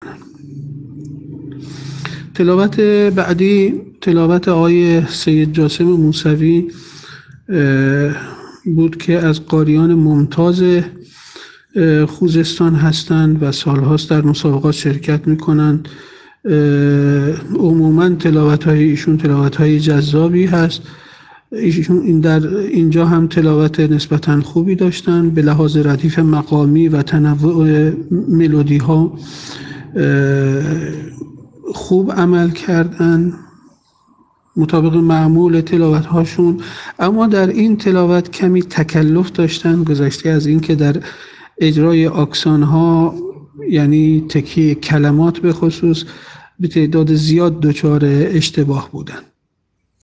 فیلم اجرای قاریان فینالیست در دومین شب مسابقات سراسری قرآن
به لحاظ ردیف مقامی و تنوع ملودی‌ها خوب عمل کرد
البته در این تلاوت کمی تکلف داشت و در اجرای آکسان‌ها هم به تعداد زیاد دچار اشتباه بود.